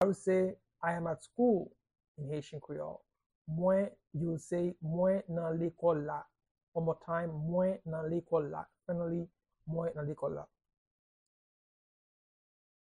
Pronunciation and Transcript:
How-to-say-I-am-at-school-in-Haitian-Creole-–-Mwen-nan-lekol-la-pronunciation-by-a-Haitian-tutor.mp3